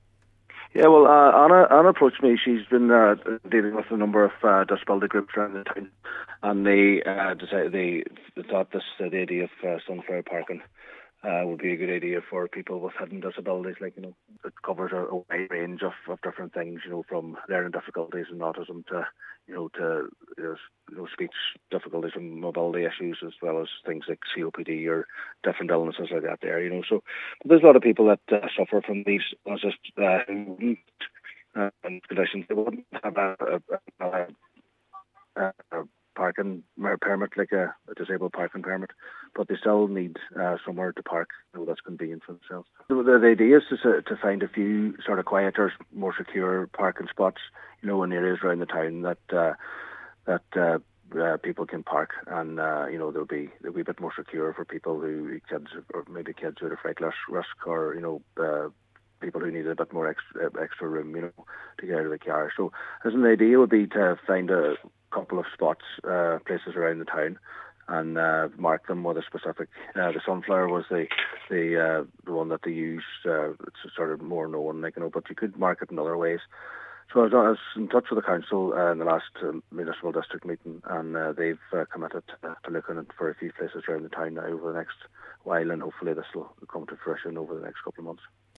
Cllr Brogan says Donegal County Council have committed to exploring the issue and hopes a number of places can be located around the town soon: